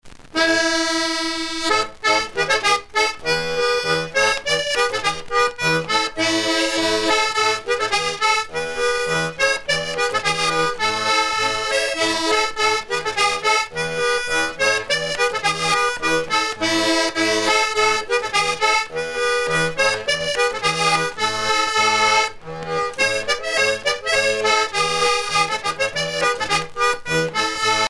danse : mazurka-polka
Pièce musicale éditée